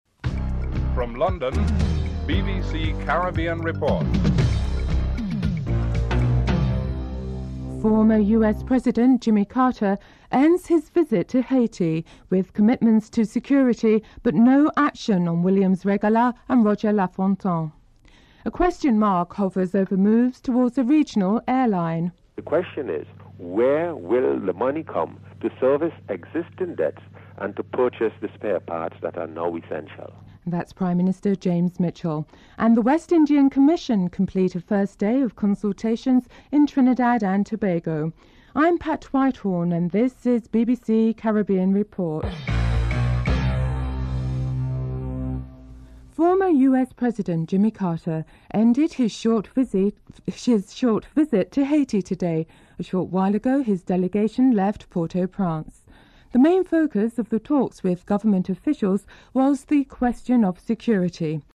The British Broadcasting Corporation
1. Headlines (00:00-00:46)